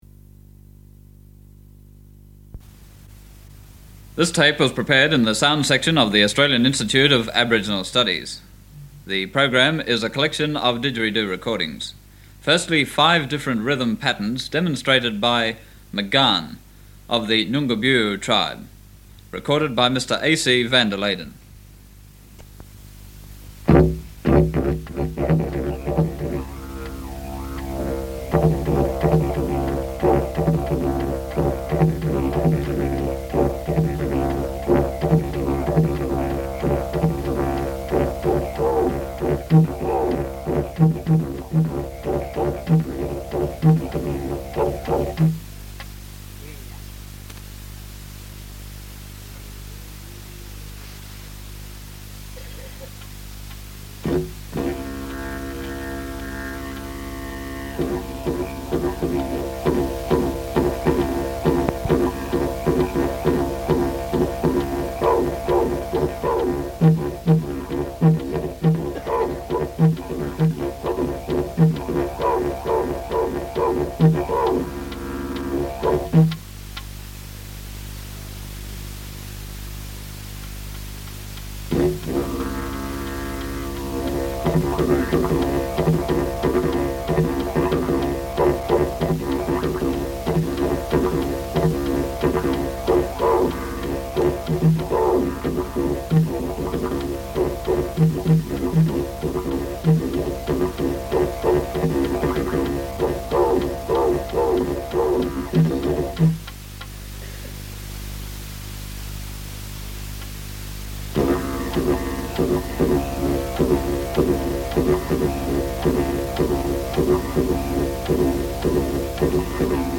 Didgeridoo music
"Didgeridoo music": collection of didgeridoo recordings prepared by the Australian Institute of Aboriginal Studies (now the Australian Institute of Aboriginal and Torres Strait Islander Studies), with commentary.
From the sound collections of the Pitt Rivers Museum, University of Oxford, being one of a number of miscellaneous or individual ethnographic field recordings (rediscovered during a recent research project).